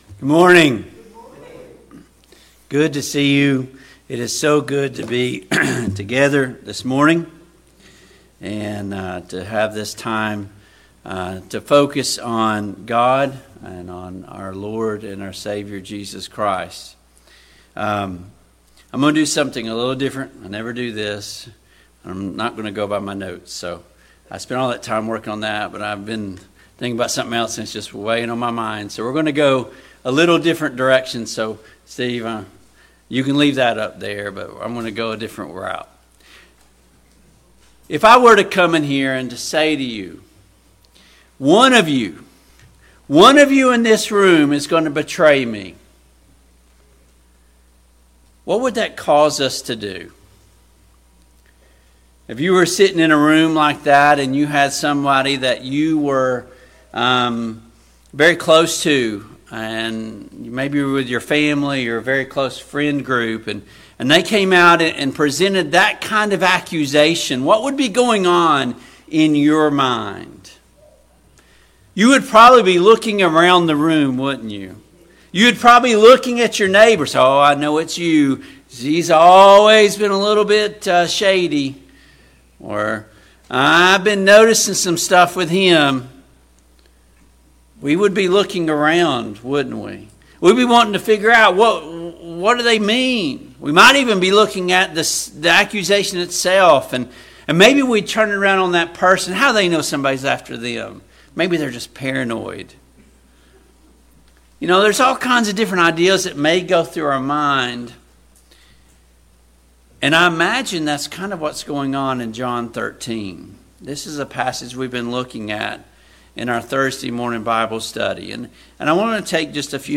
John 13 Service Type: AM Worship Topics: Jesus washes the feet of the disciples « 6.